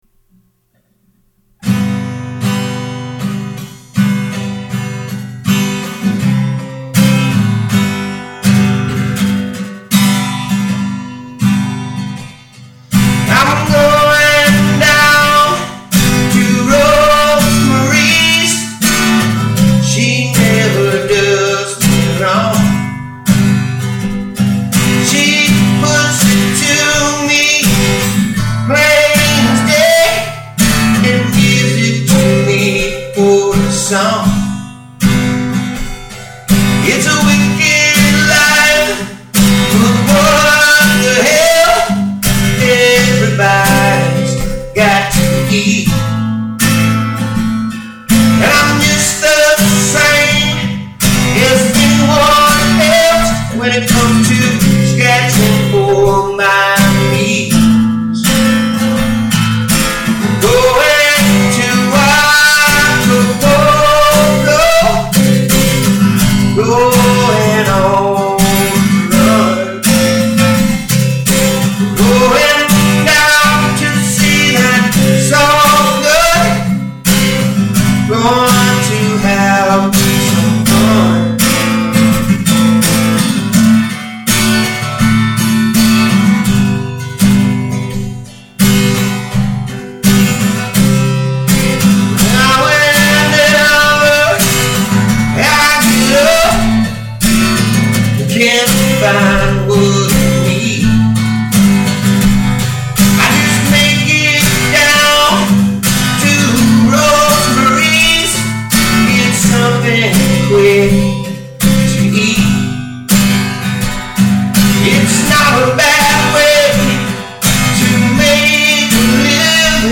Country-rock